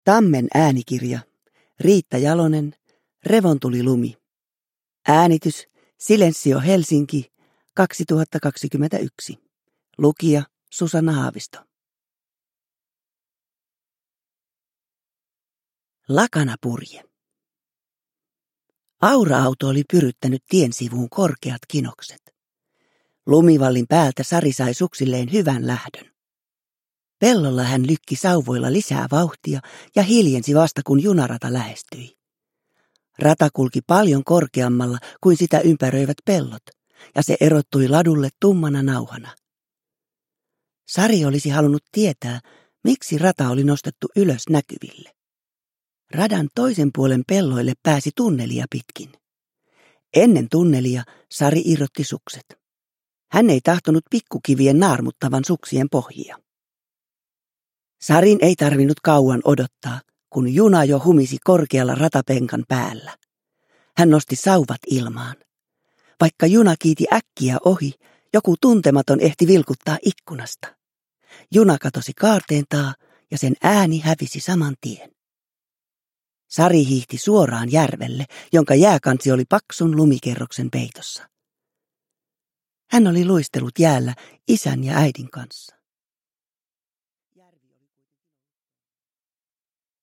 Uppläsare: Susanna Haavisto